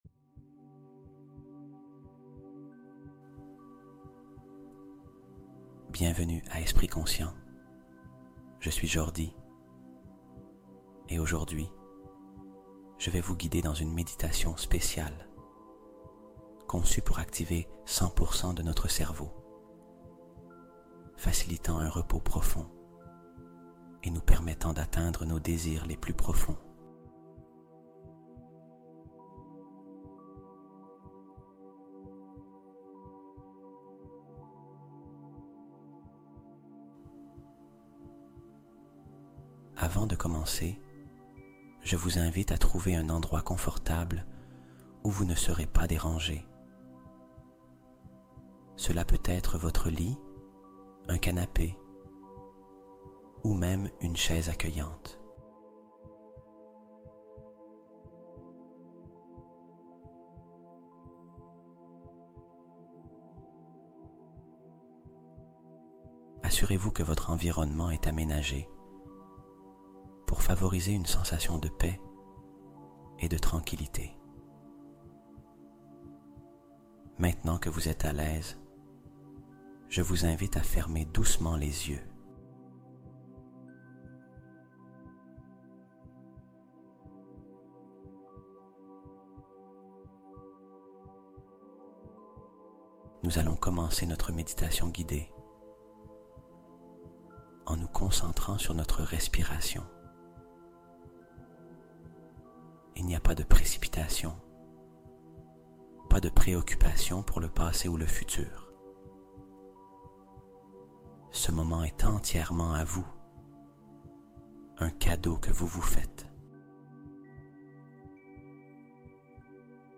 Sommeil et Rêves : Utiliser la fréquence 432 Hz pour réaliser ses projets